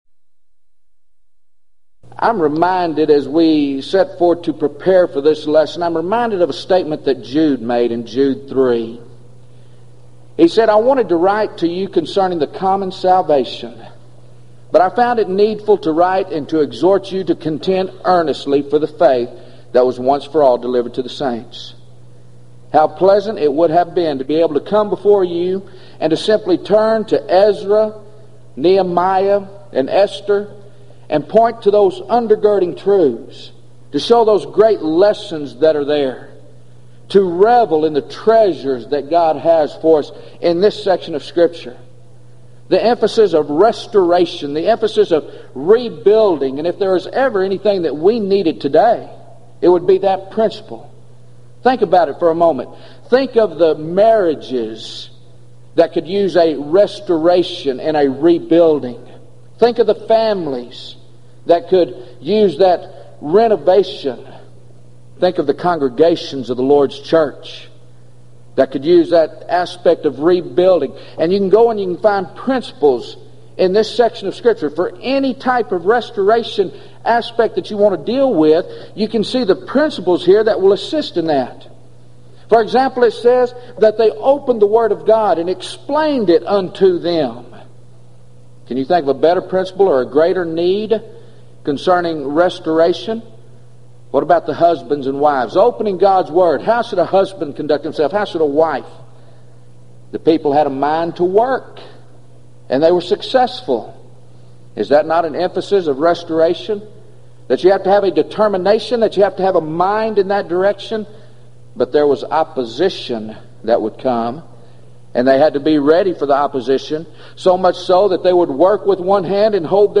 Event: 1995 Gulf Coast Lectures
lecture